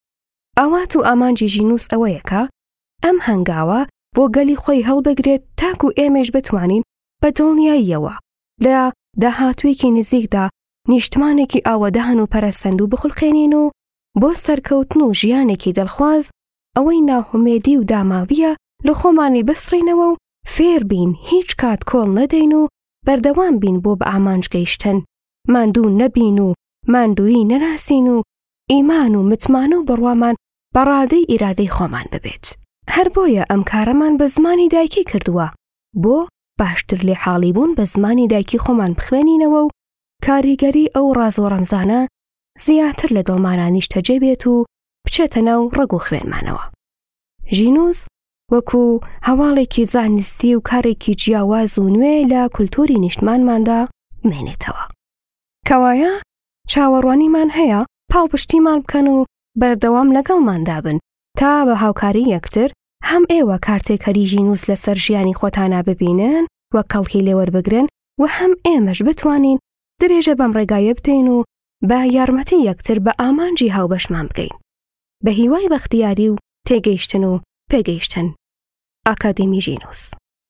Female
Adult